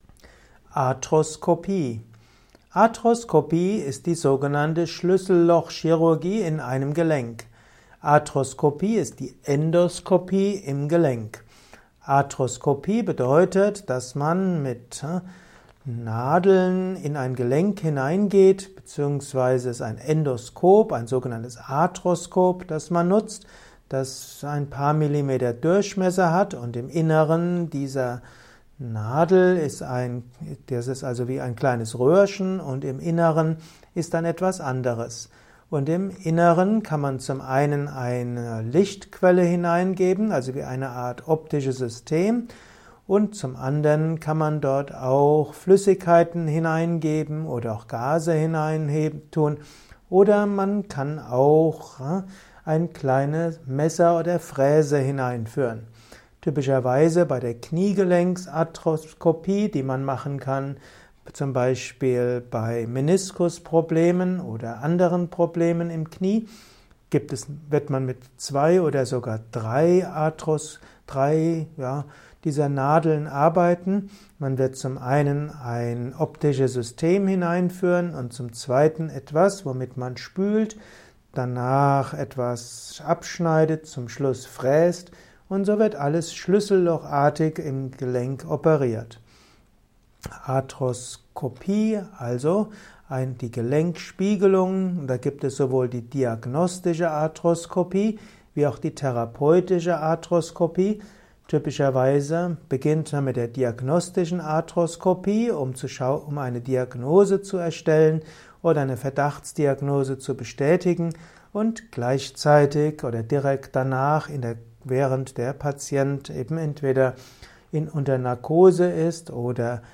Audiovortrag zum Thema Arthroskopie
Er ist ursprünglich aufgenommen als Diktat für einen